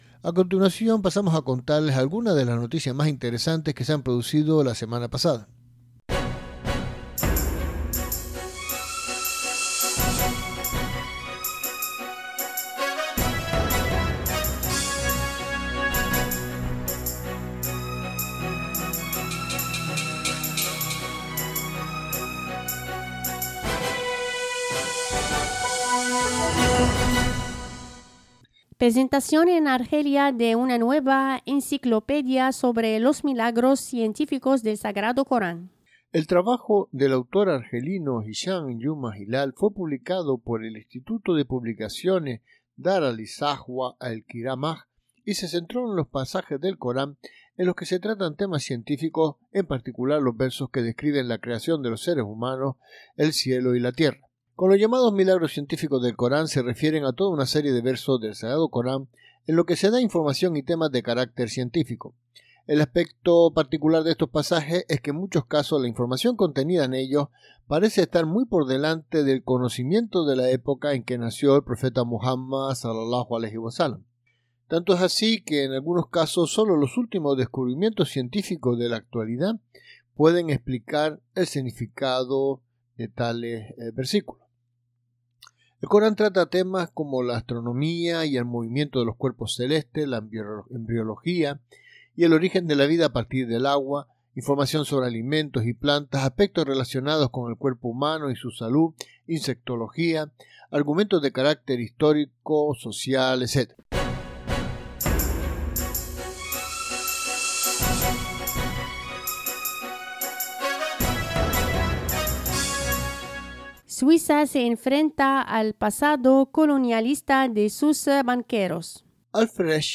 Resumen de las noticias más destacadas de la semana recogida por Radio 2 Luces. Actualidad, economía, política, religión, curiosidades,....